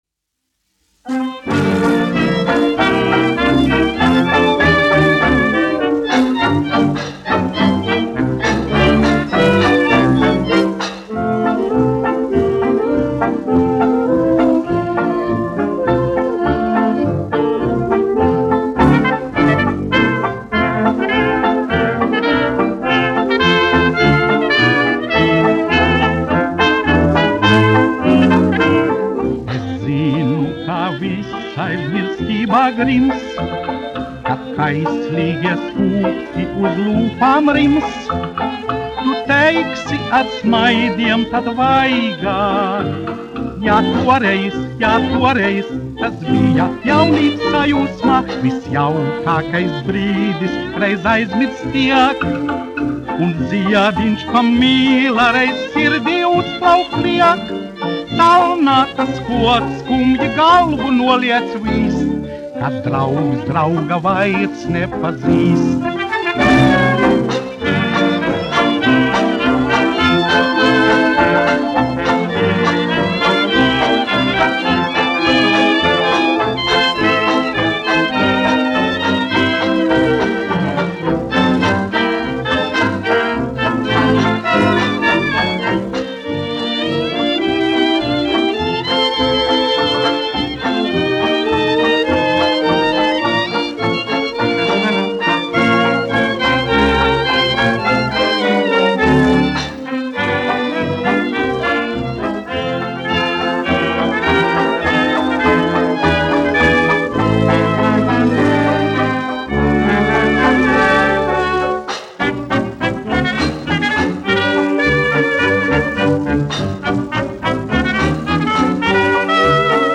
1 skpl. : analogs, 78 apgr/min, mono ; 25 cm
Fokstroti
Populārā mūzika
Skaņuplate